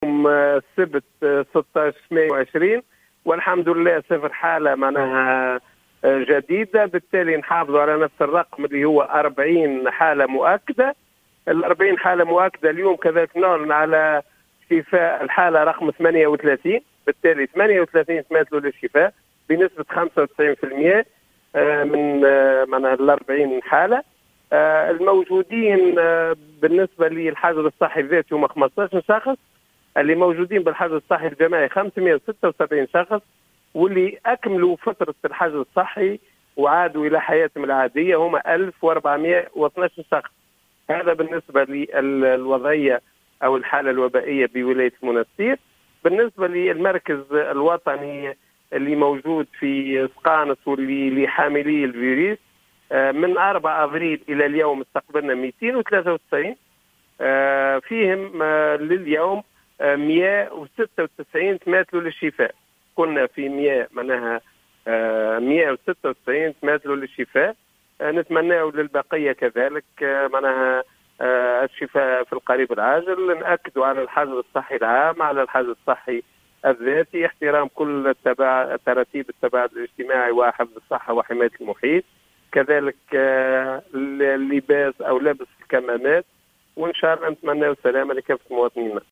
أكد المدير الجهوي للصحة بالمنستير، المنصف الهواني في تصريح اليوم لـ"الجوهرة أف أم" عدم تسجيل إصابات جديدة بفيروس "كورونا".